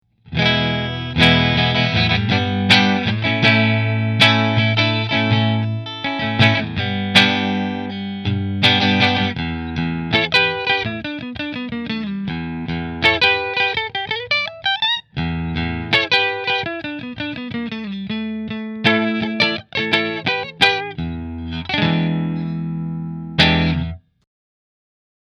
• Stacked Mahogany Body with a Flame Maple Top
• HSS Rio Grande Pickup Configuration
Rahan Guitars RP Double Cutaway Green Flame Maple Top Position 3 Through Fender